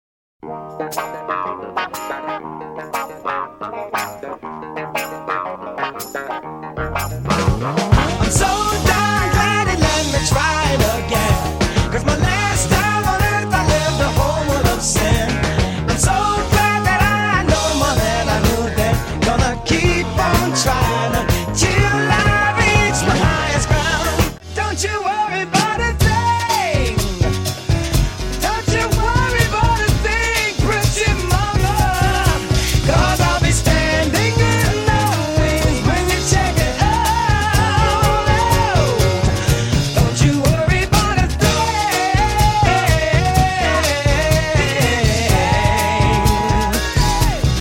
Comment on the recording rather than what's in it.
I play two songs in the jukebox